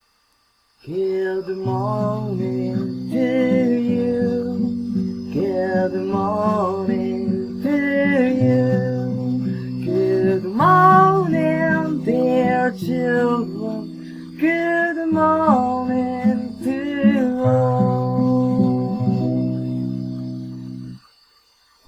GoodMorningToAll_1893_song.mp3